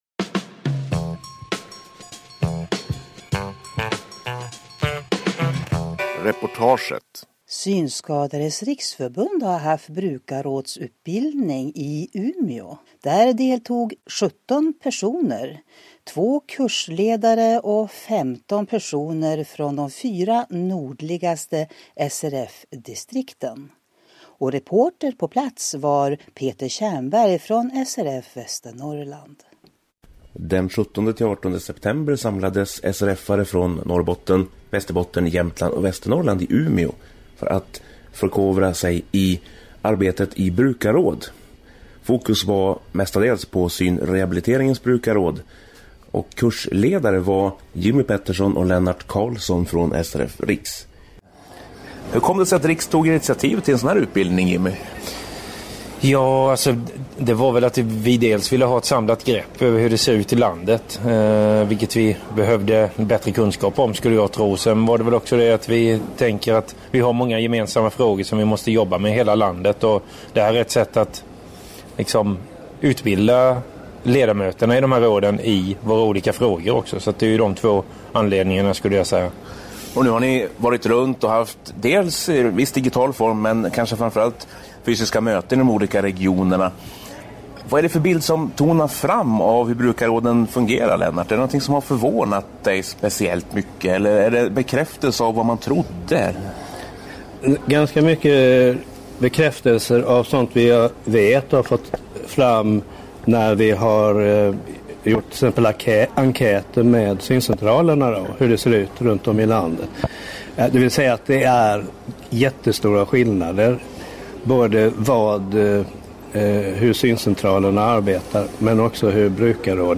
Synskadades Riksförbund arrangerade nyligen en brukarrådsutbildning för SRF medlemmar i de fyra nordligaste distrikten. 17 personer deltog varav 2 kursledare. Reporter på plats i Umeå